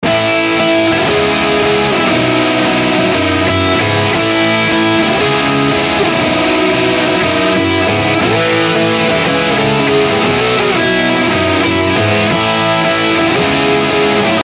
Normal-drive.mp3